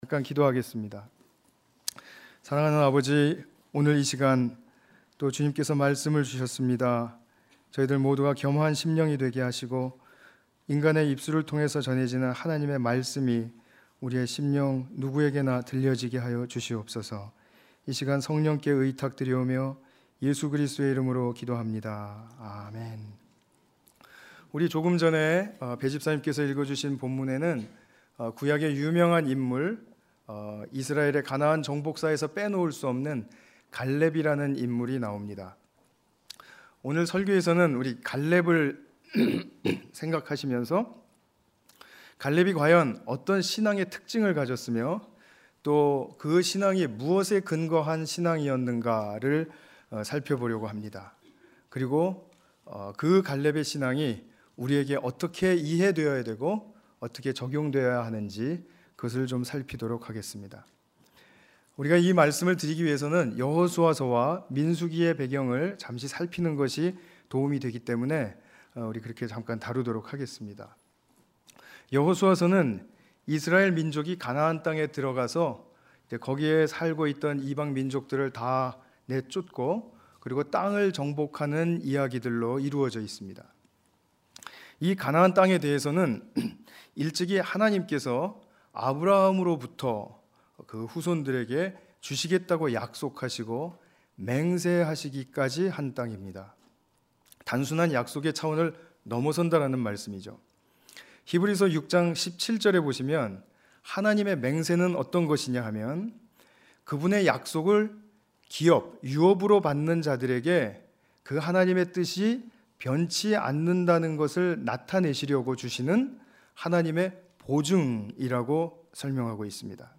주일예배